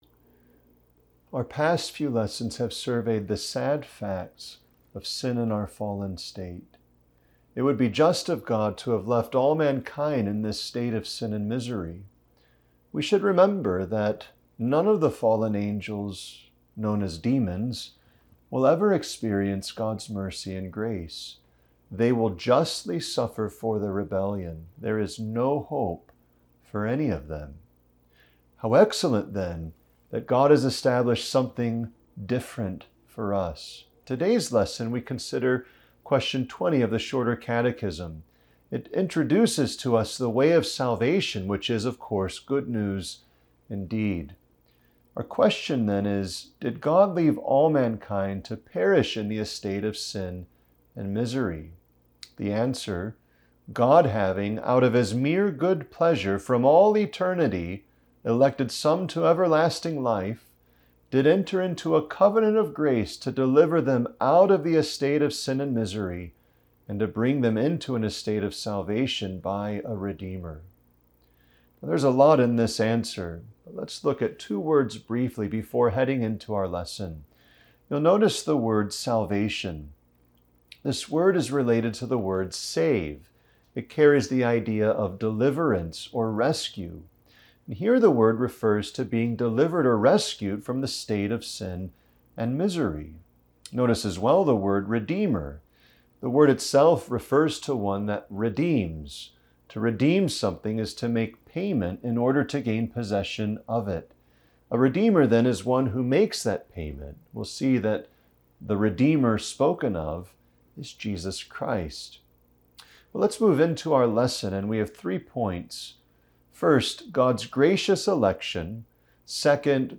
Lesson Downloads